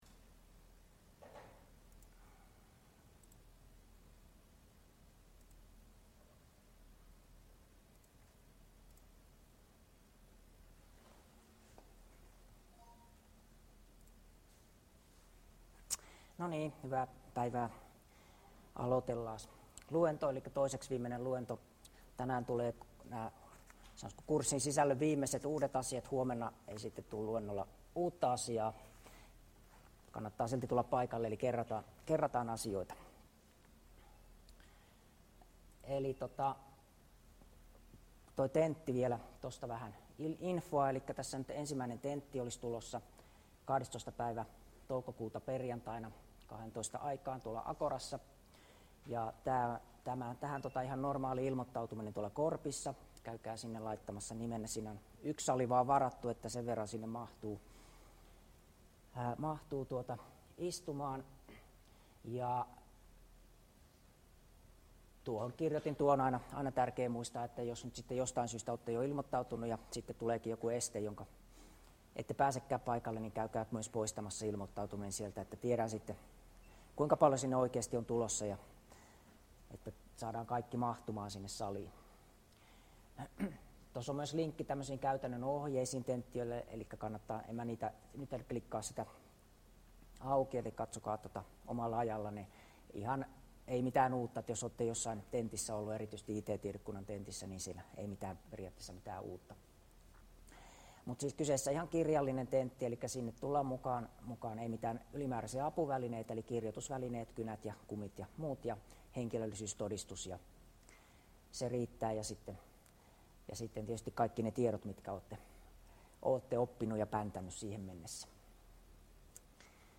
Luento 13 — Moniviestin